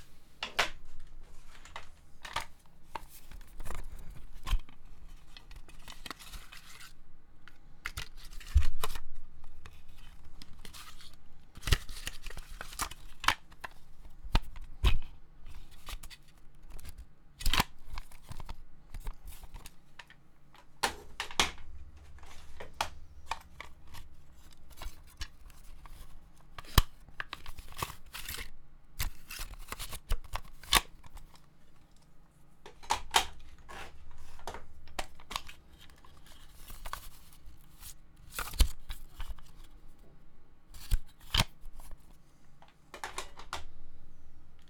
• compact cassettes tape case play.wav
Joggling with cases from old tape compact cassettes, recorded near field with a Sterling ST 66 mic.
audi_cassettes_play_vuB.wav